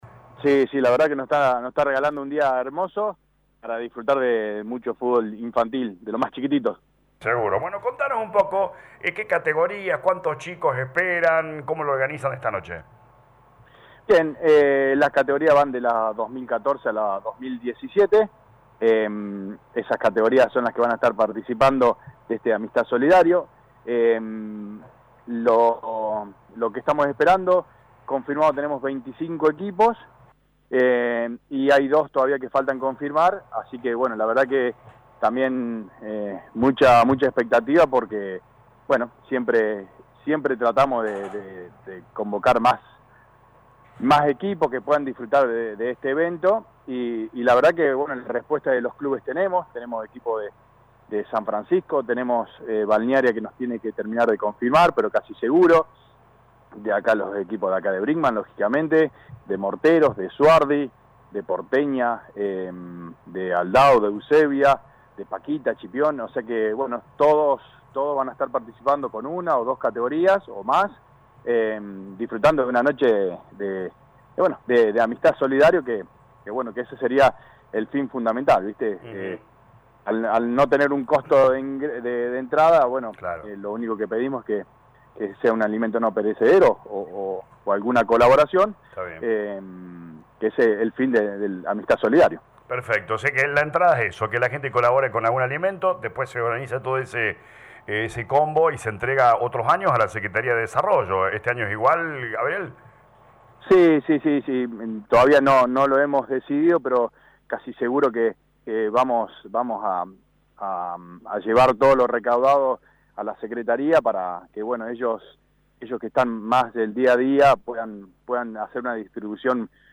En diálogo con LA RADIO 102.9